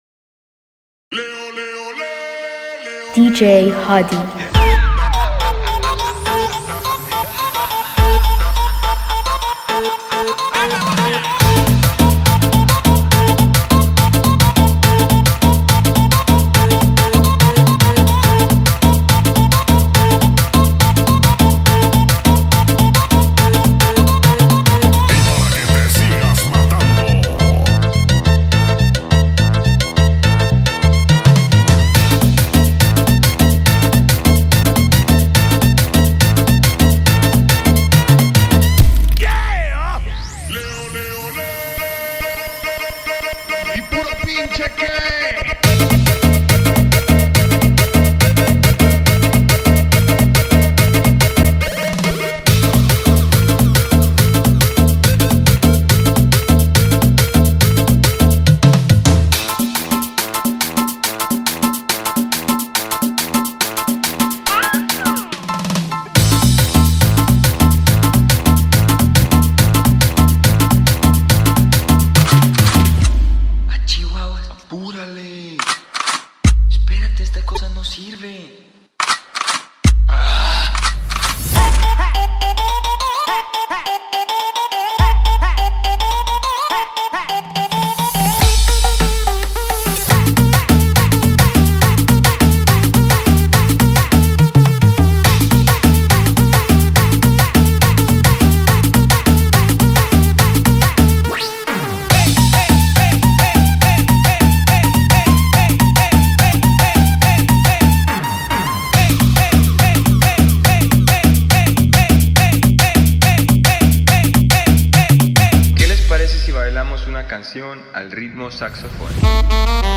ریمیکس شاد بی کلام تریبال دنس
آهنگ تریبال شاد بی کلام